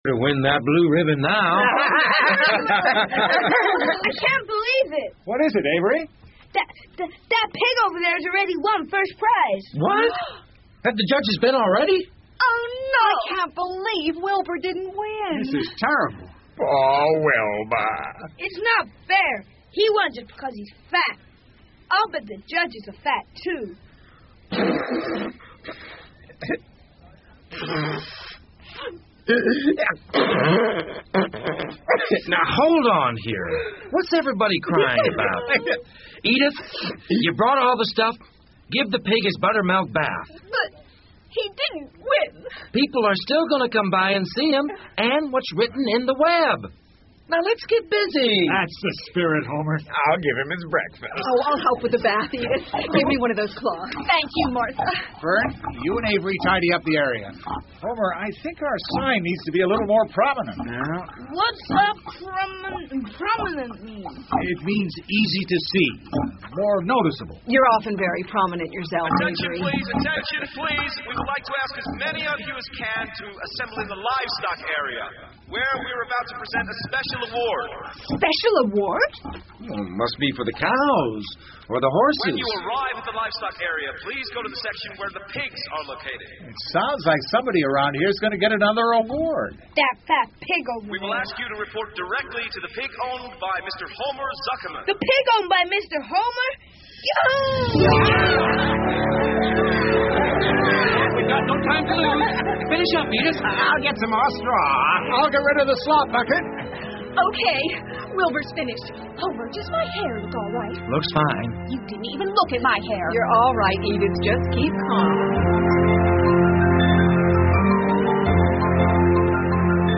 夏洛的网 Charlottes Web 儿童广播剧 15 听力文件下载—在线英语听力室